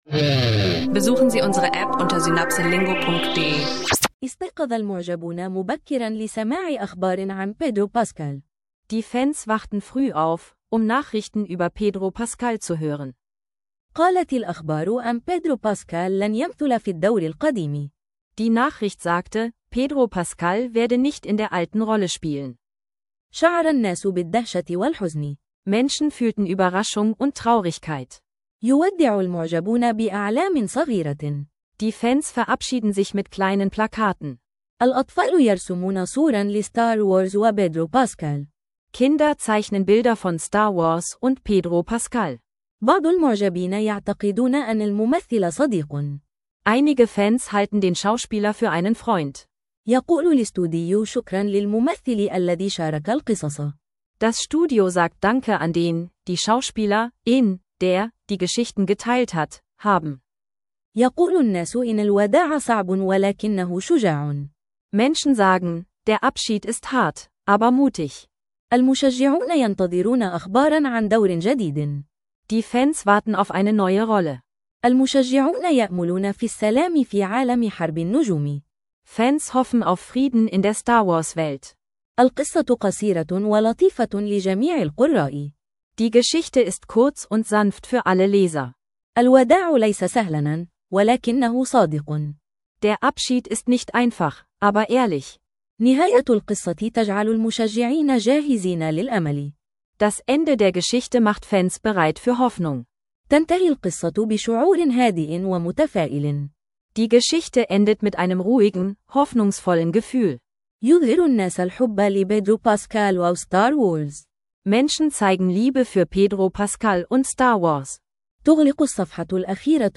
Eine geführte, klare Lernepisode über Abschied und Hoffnung – Arabisch lernen online mit dem Fokus auf Arabisch lernen mit Podcast